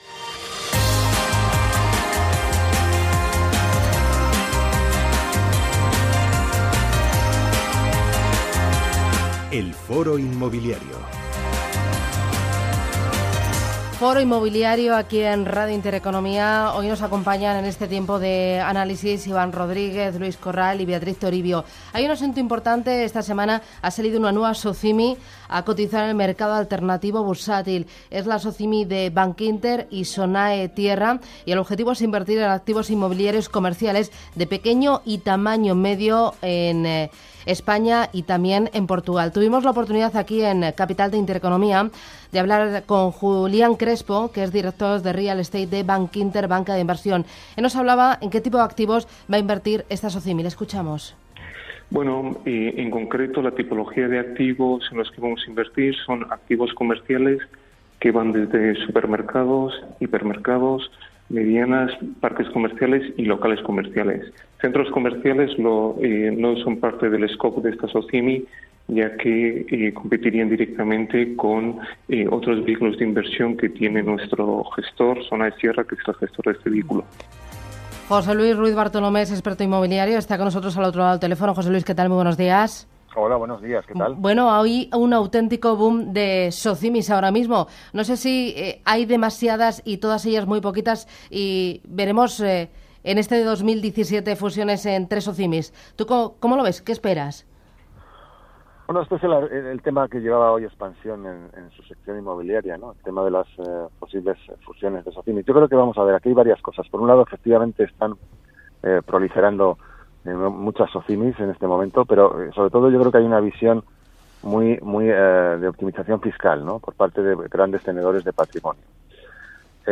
El reciente estudio de Fotocasa y otros temas de interés se han tratado en el programa ForoInmobiliario de Intereconomia Radio.